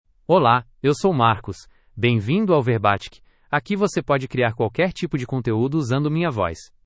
MalePortuguese (Brazil)
MarcusMale Portuguese AI voice
Voice sample
Male
Marcus delivers clear pronunciation with authentic Brazil Portuguese intonation, making your content sound professionally produced.